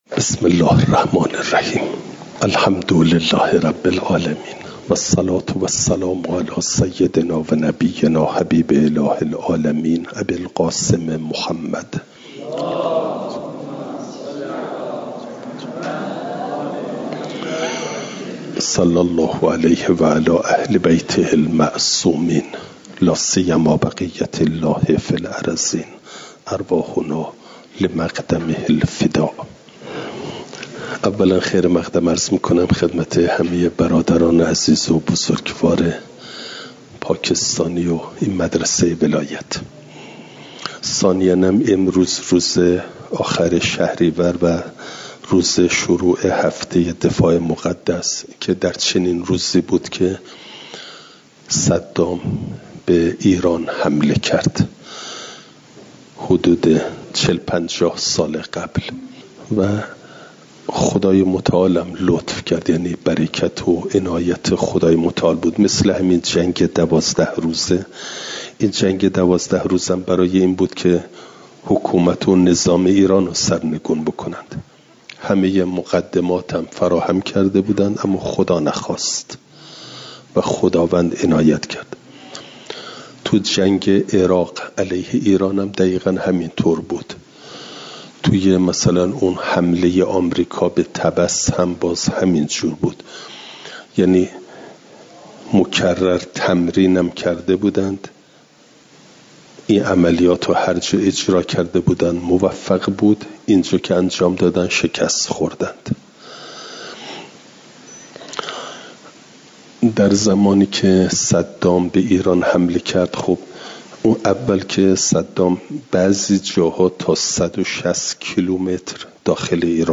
دوشنبه ۳۱ شهریورماه ۱۴۰۴، دارالقران علامه طباطبایی(ره)